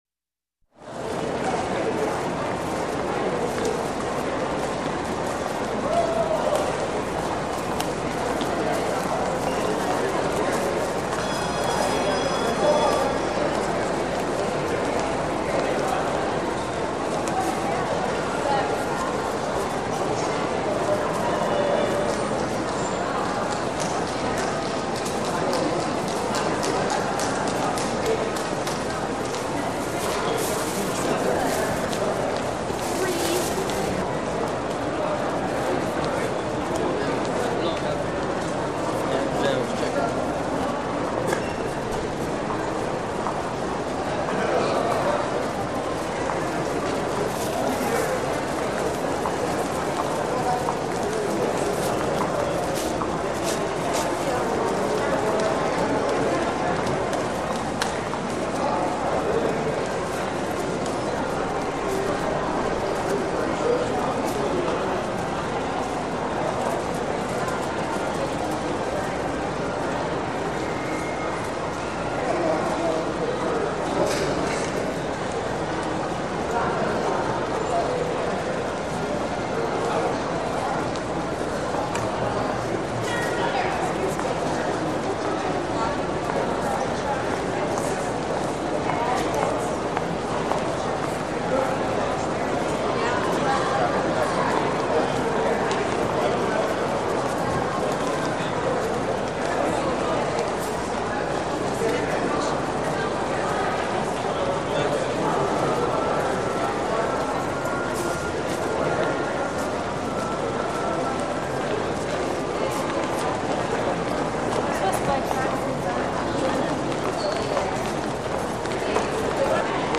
Магазины звуки скачать, слушать онлайн ✔в хорошем качестве